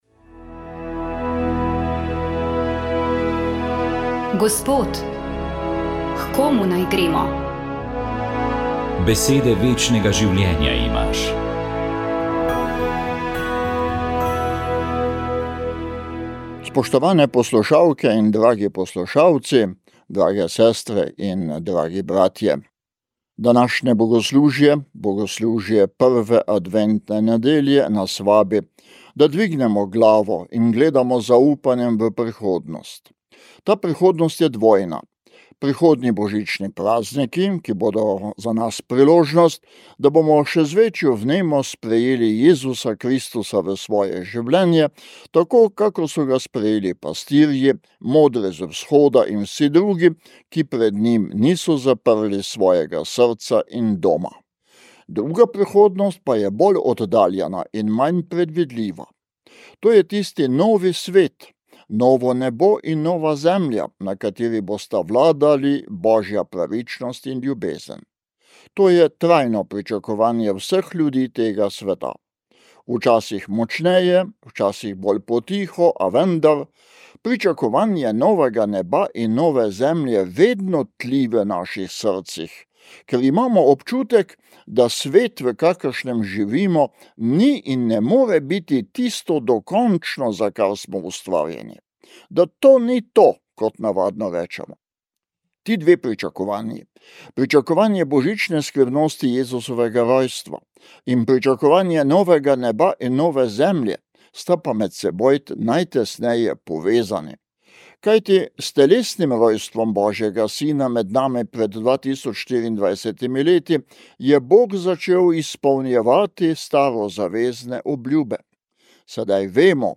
Duhovni nagovor
Upokojeni ljubljanski nadškof msgr. dr. Anton Stres je v nagovoru na 2. adventno nedeljo, ko obhajamo praznik Marijinega brezmadežnega spočetja, razmišljal o širšem pomenu in razumevanju tega praznika. Verska resnica o Marijinem brezmadežnem spočetju ne meri samo na trenutek spočetja, samo na začetek njenega življenja, ampak označuje in zaznamuje vse njeno življenje.